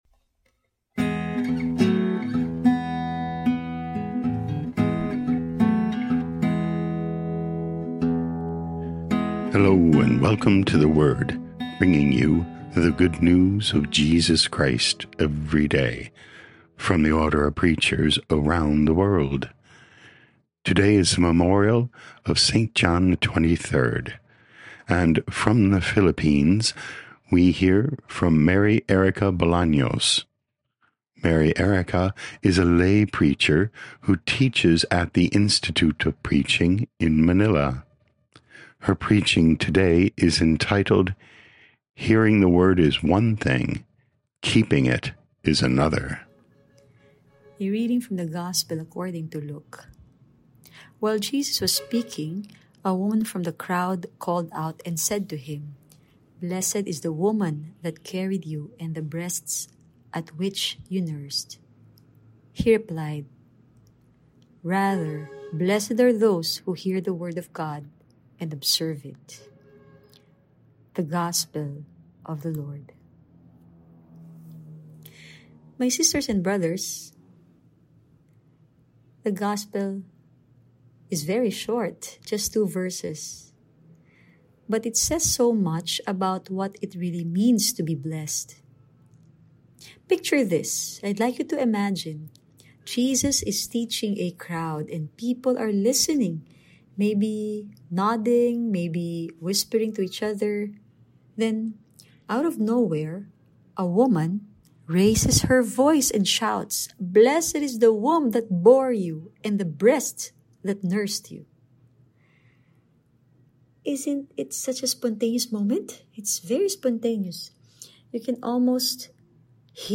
11 Oct 2025 Hearing the Word is one thing, keeping it is another Podcast: Play in new window | Download For 11 October 2025, The Memorial of Saint John XXIII, based on Luke 11:27-28, sent in from Manila, Philippines.